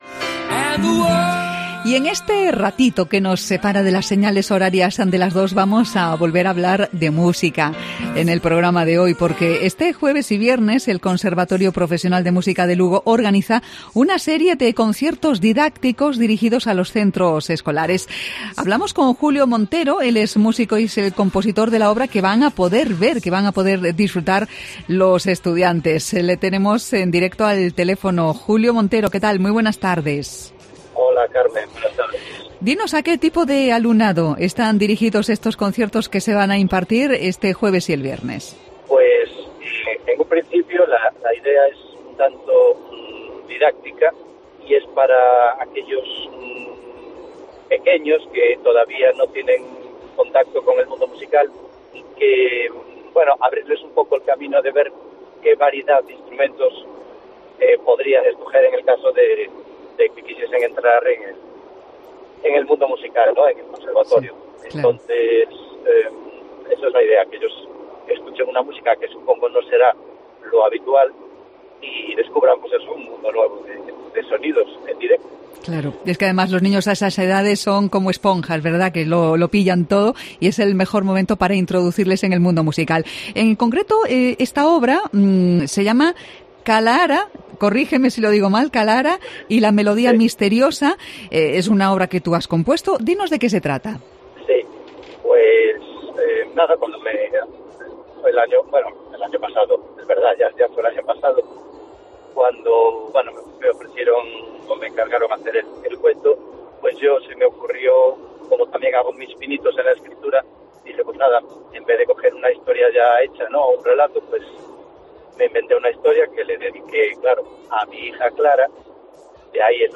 compositor